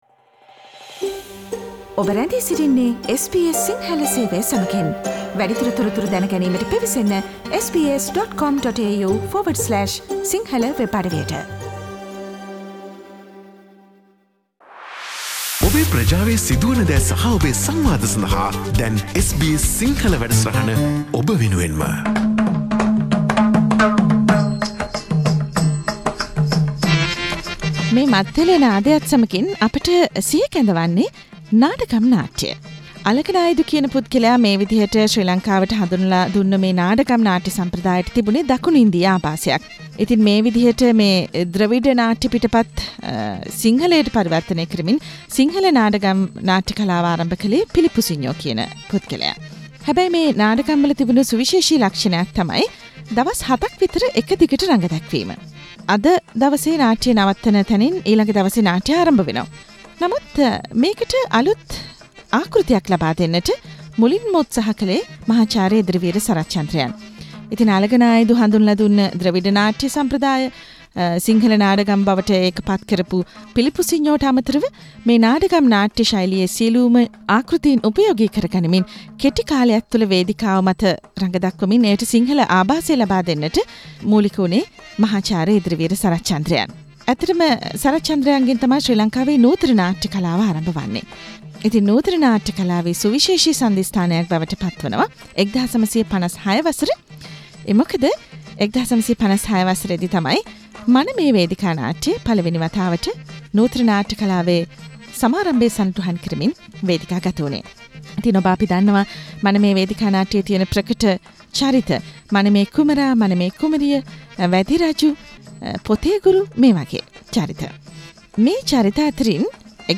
SBS Sinhala radio interviewed